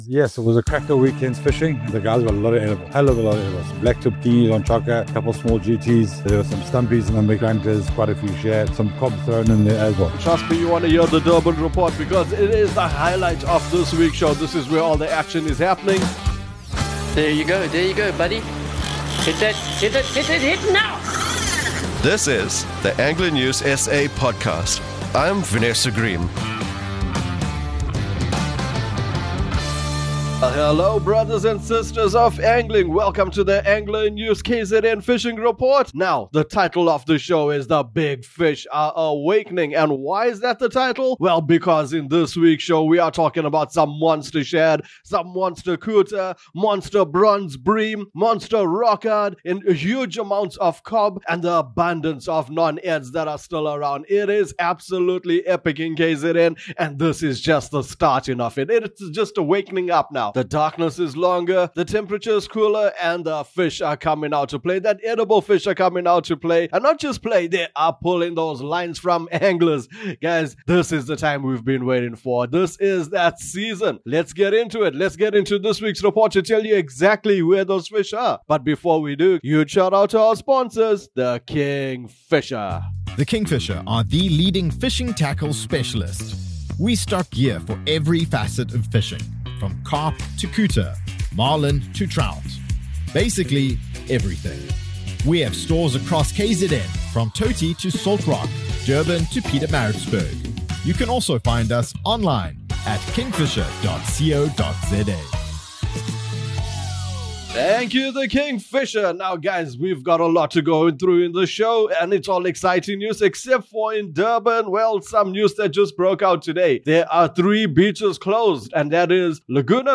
This episode of the ANSA KZN report our reporters deliver thrilling updates from the coastline and midlands, signaling the start of the exciting fishing season.